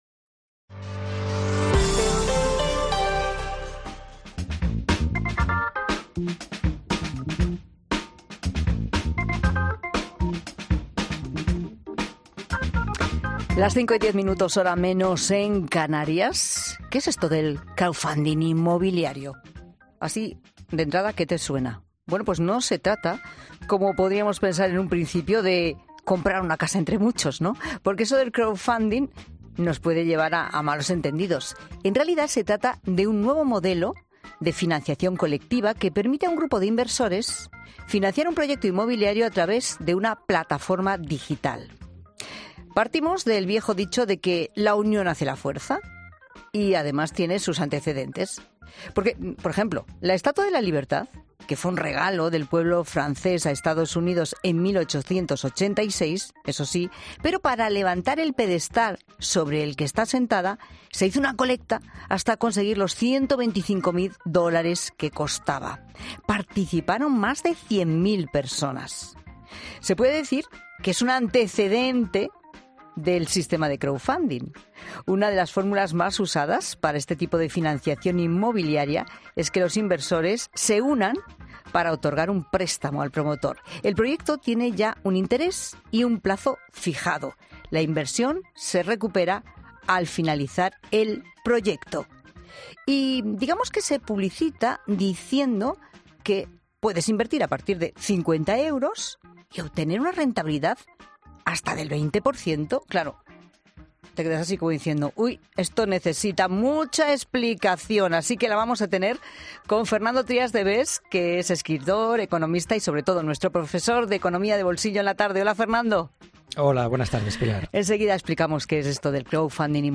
El economista Trías de Bes explica en La Tarde de COPE una forma de invertir no exenta de riesgo, pero que puede implicar una gran rentabilidad